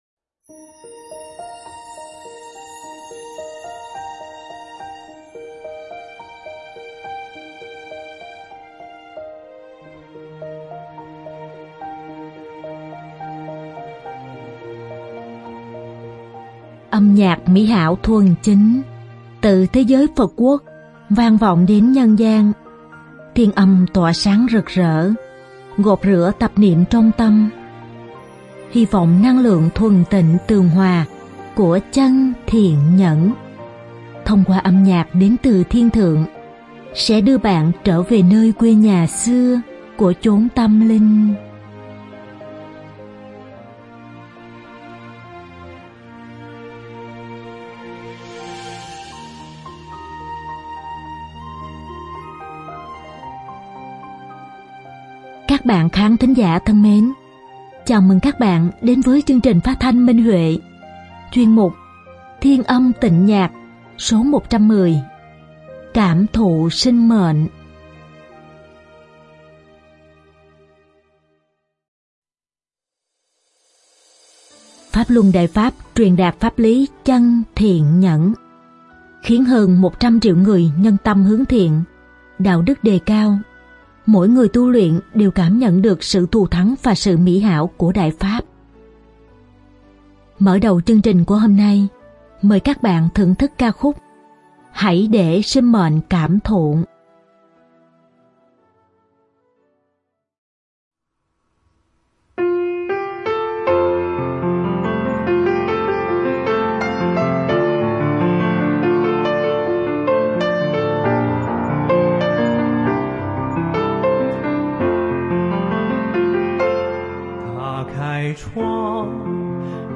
chương trình phát thanh
Piano